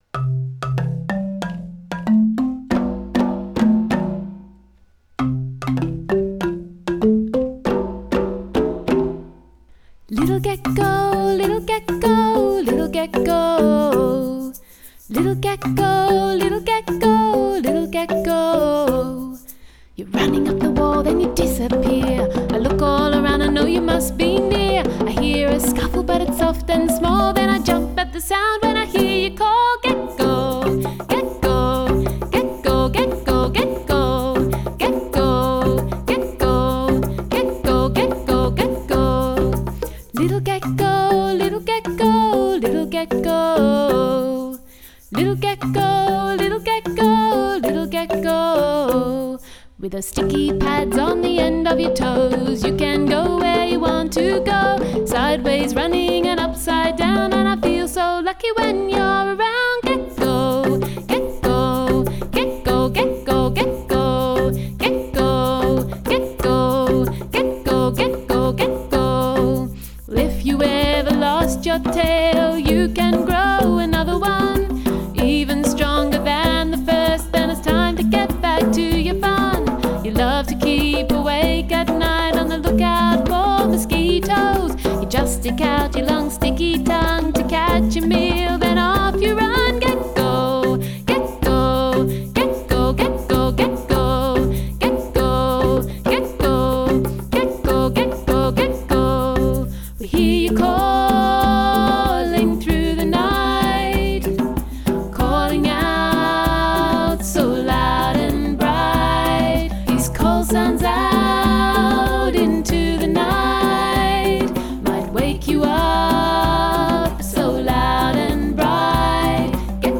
parent friendly children’s album